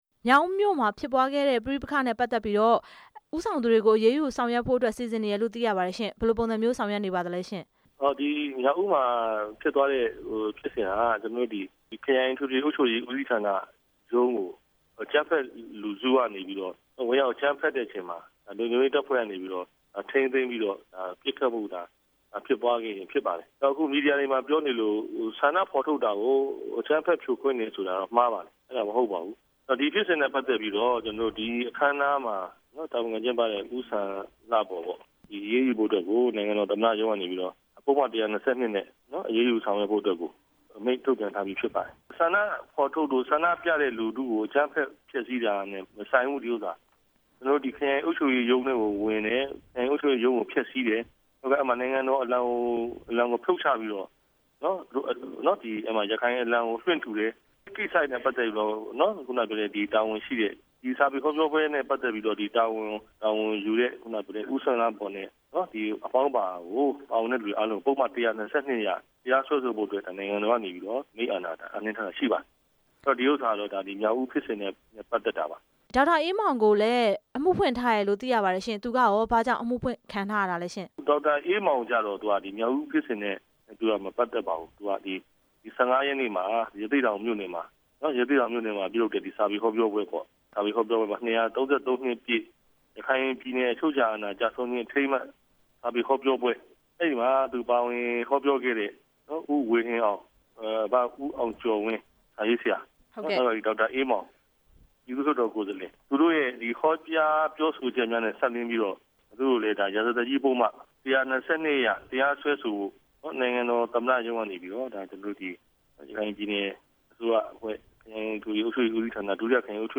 မြောက်ဦးဟောပြောပွဲစီစဉ်သူတွေကို တရားစွဲမယ့်အကြောင်း မေးမြန်းချက်